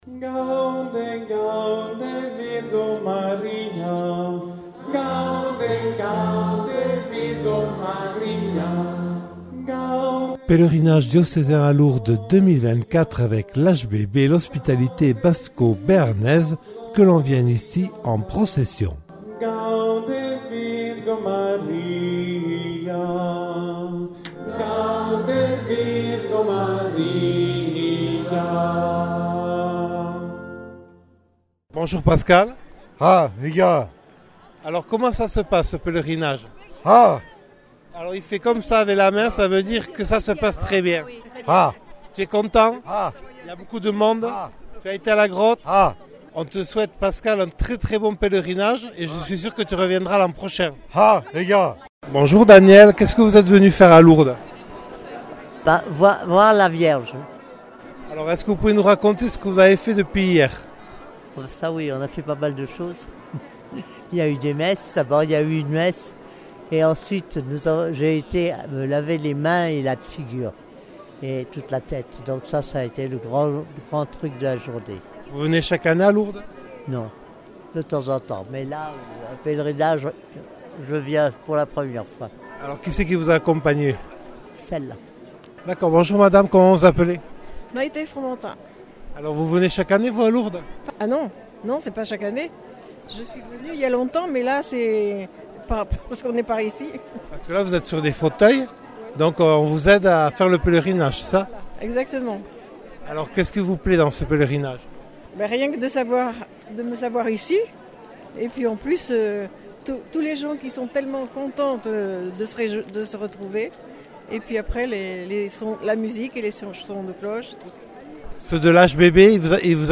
2ème reportage : personnes porteuses d’un handicap ; HBB Saint-Jean-de-Luz ; des jeunes du lycée Saint-Joseph et Armand David à Hasparren.